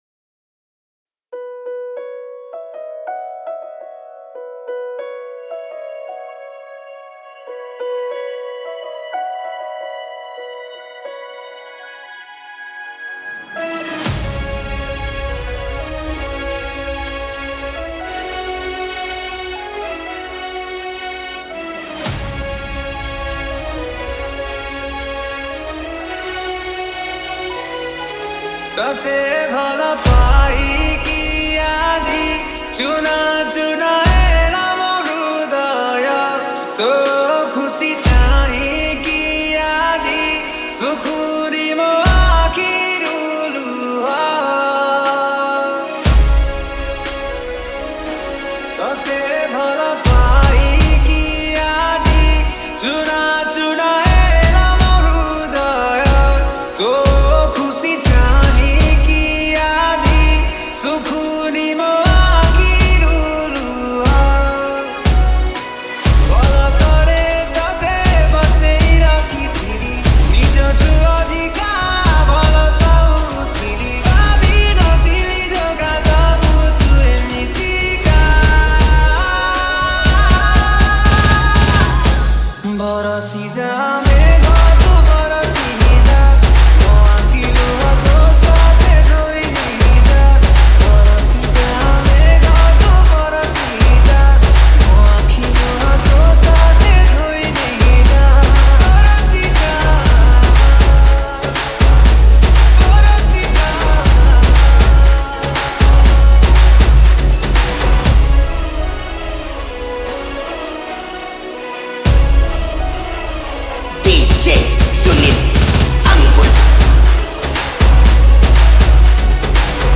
Category:  New Odia Dj Song 2025